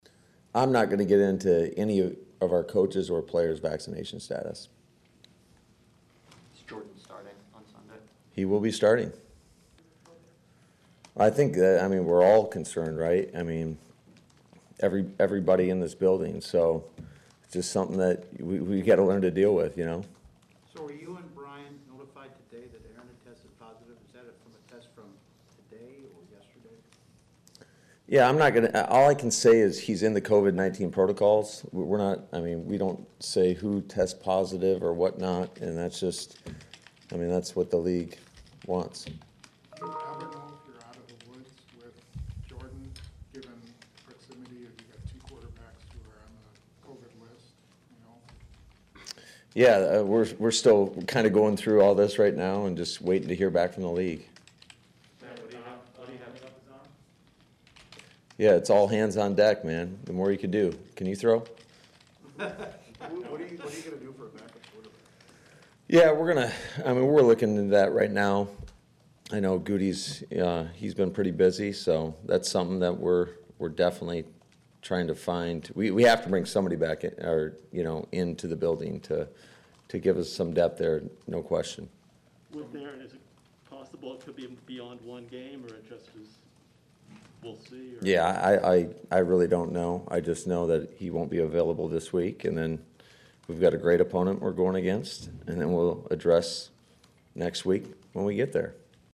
Just after the noon hour, LaFleur appeared before the media for his daily press conference and as expected, was peppered with questions about Rodgers, the test result and the nation-wide controversial issue of whether or not he’s been vaccinated.